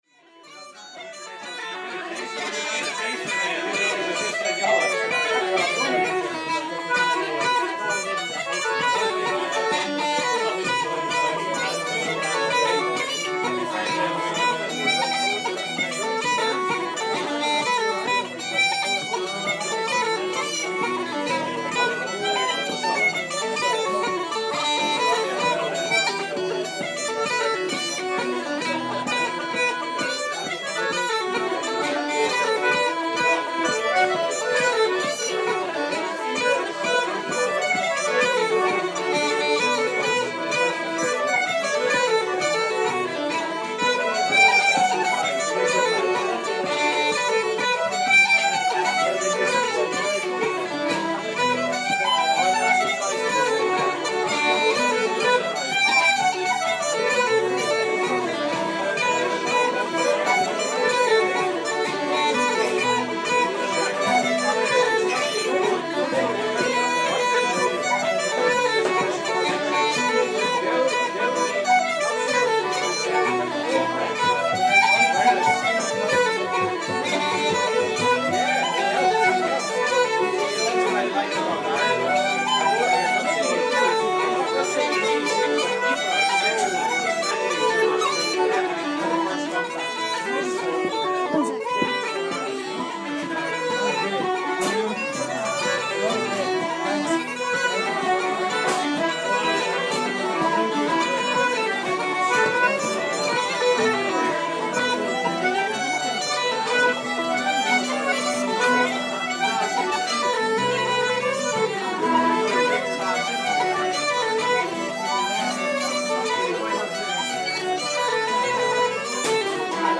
It was a nice relaxed session considering I had been on the road since 7AM that morning.
A few of my favorite jigs. Played on the pipes. on Wednesday the 8th of June in the Cobblestone in Dublin.